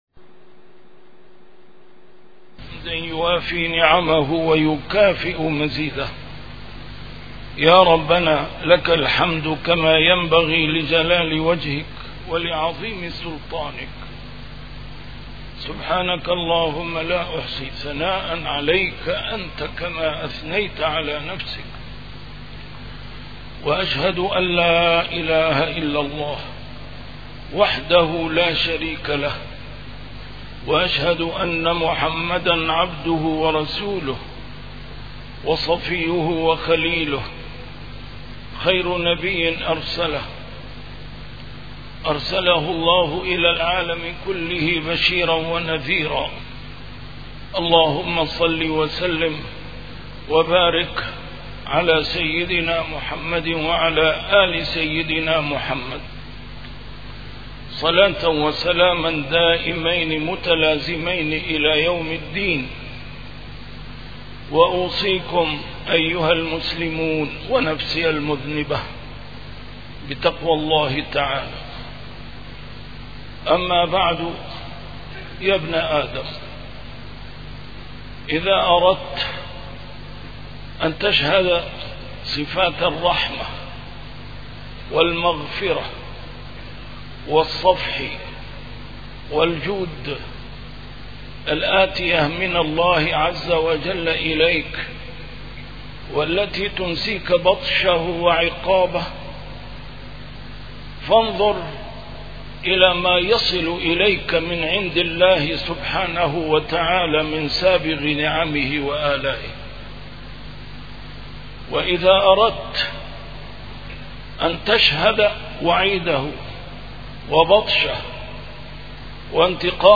A MARTYR SCHOLAR: IMAM MUHAMMAD SAEED RAMADAN AL-BOUTI - الخطب - وعد ووعيد